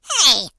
share/hedgewars/Data/Sounds/voices/HillBilly/Ow1.ogg
07d83af9d2a8 Add hillbilly voice